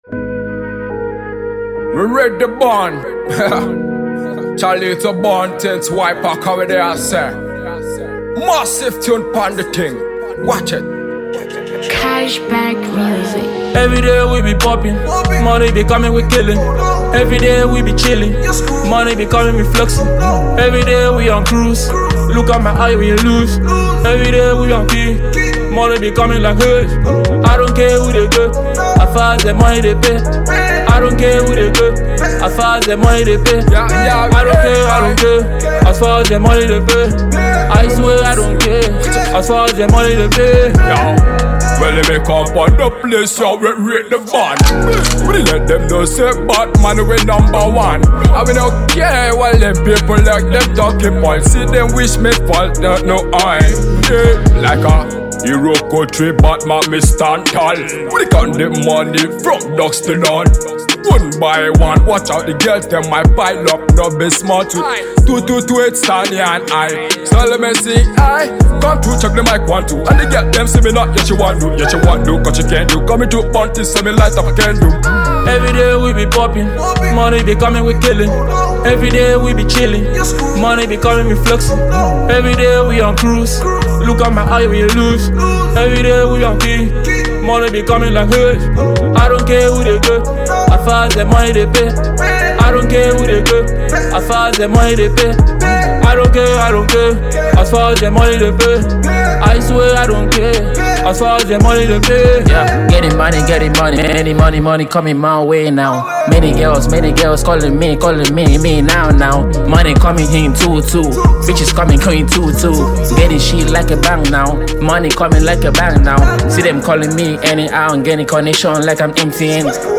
Ragae dance hall act